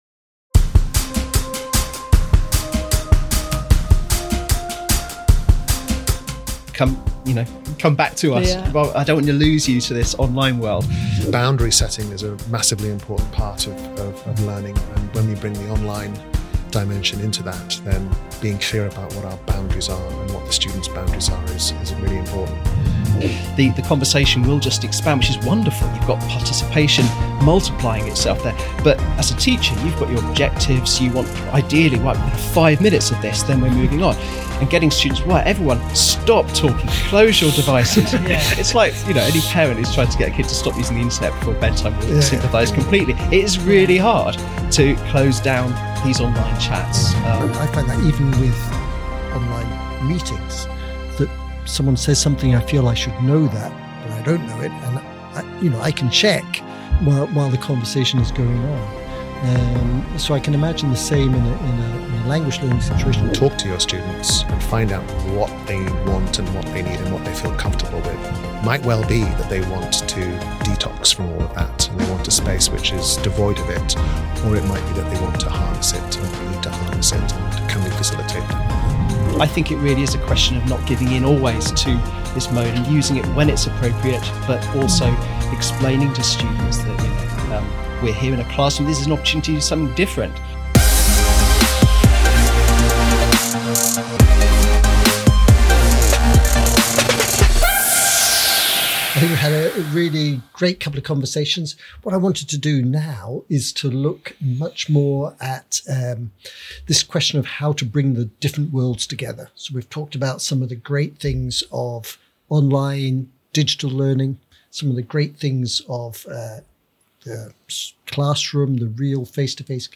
The panel also discusses the risks of over-reliance on digital tools, the importance of boundaries, and how to maintain classroom focus and inclusivity in tech-rich environments.